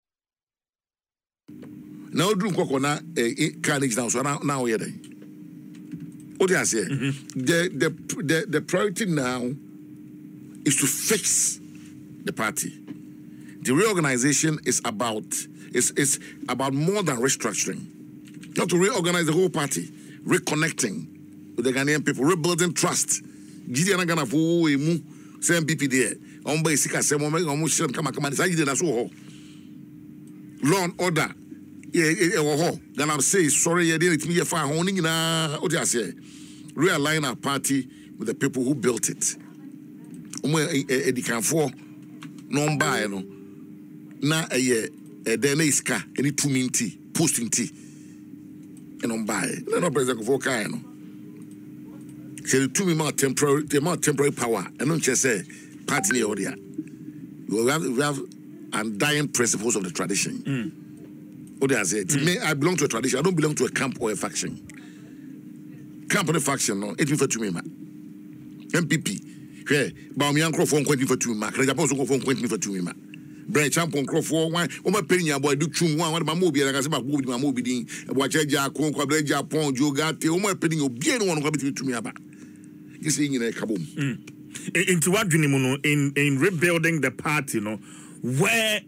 Speaking in an interview on Adom FM’s Dwaso Nsem, the former Deputy Minister for Works and Housing stressed the need for collaboration among all party stakeholders, cautioning that internal divisions could derail the party’s chances.